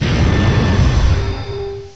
cry_not_necrozma_ultra.aif